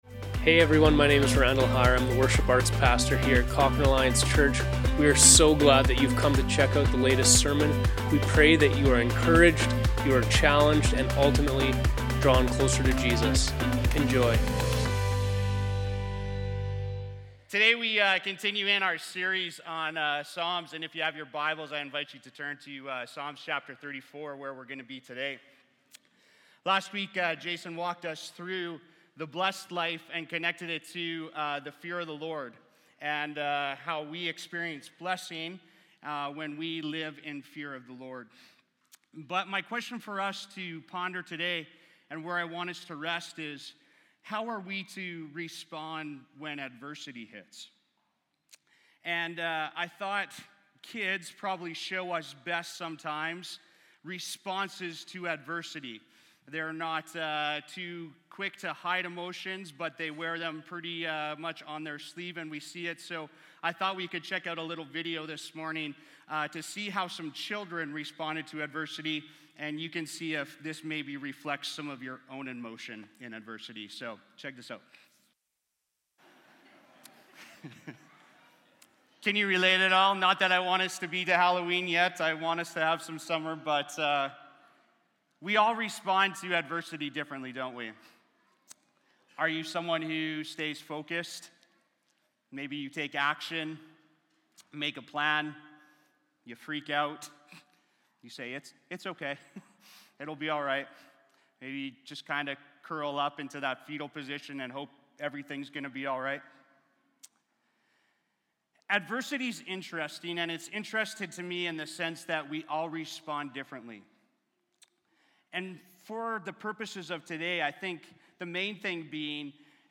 Cochrane Alliance Church Sermons | Cochrane Alliance Church